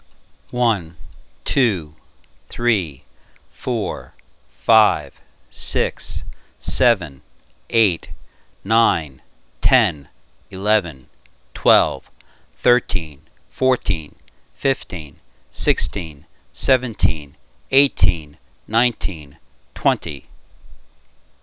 Sound Editing Example
Normal 1-20 count